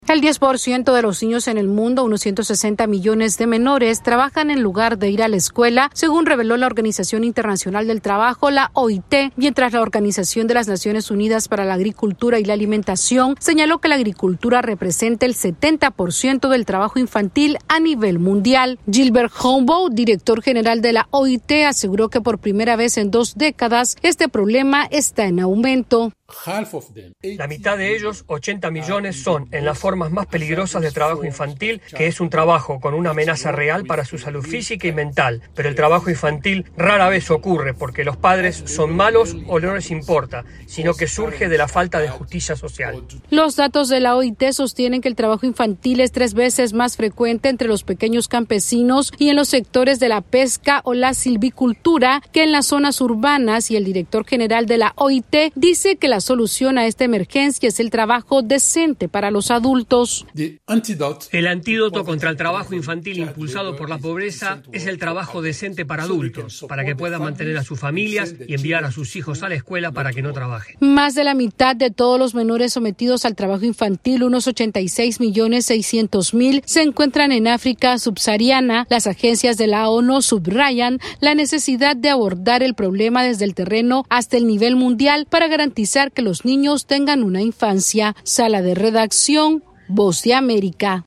AudioNoticias
Por primera vez en 20 años el trabajo infantil está aumentando, y según datos de la OIT, uno de cada diez niños trabaja en lugar de ir a la escuela. Esta es una actualización de nuestra Sala de Redacción...